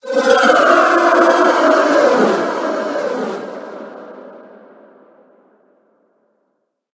Cri de Charmilly Gigamax dans Pokémon HOME.
Cri_0869_Gigamax_HOME.ogg